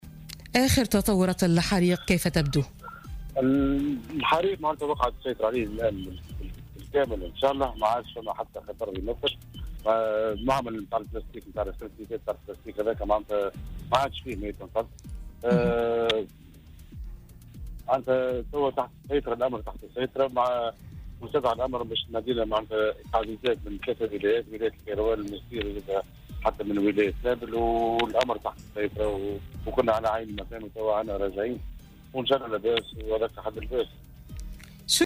أكد والي سوسة عادل الشليوي، في تصريح لـ "الجوهرة اف أم" انه تم السيطرة بالكامل في ساعة متاخرة من اليوم السبت، على حريق في مصنع بالقلعة الكبرى.